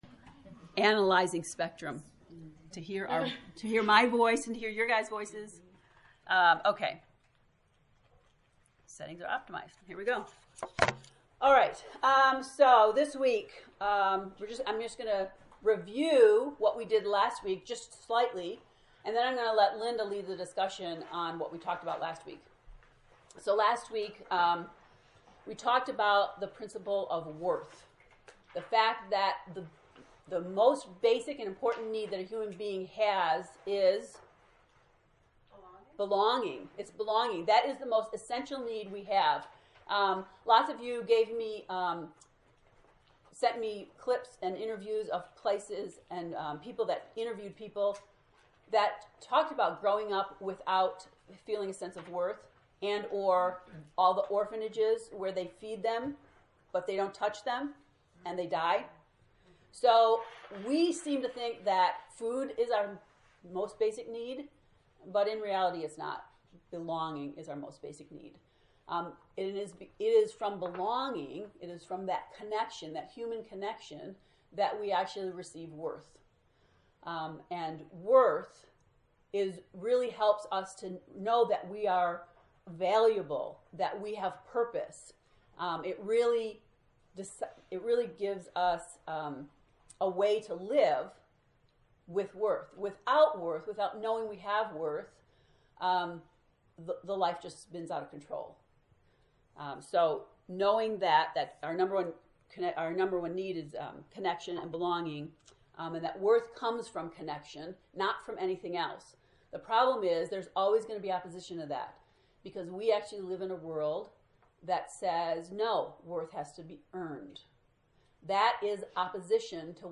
To listen to the lecture from lesson 1 “A Promise of Life” click below: